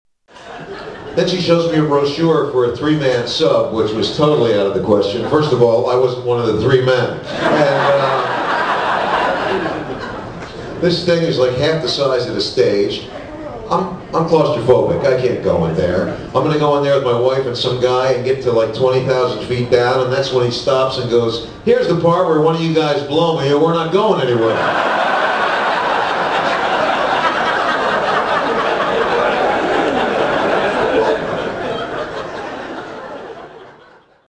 Tags: Comedian Robert Schimmel clips Robert Schimmel audio Stand-up comedian Robert Schimmel